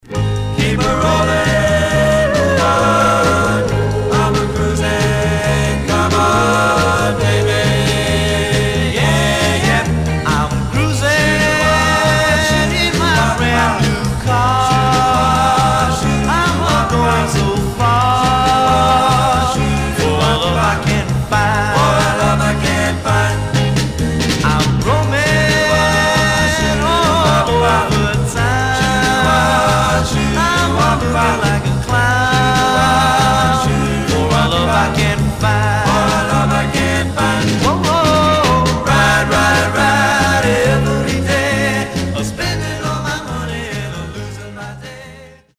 Condition: M- SURF
Stereo/mono Mono